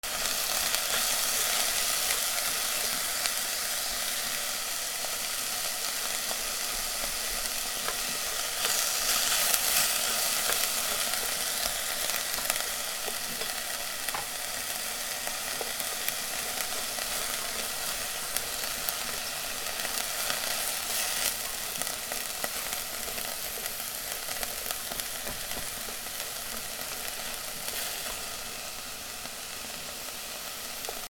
たまねぎを炒める
『ジュー』